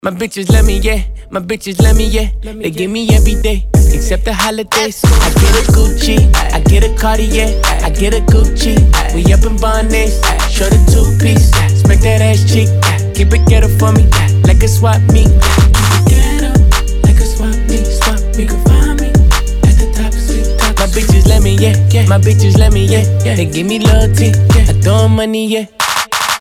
ритмичные
Хип-хоп
качающие